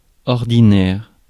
Ääntäminen
France (Paris): IPA: [ɔʁ.di.nɛʁ]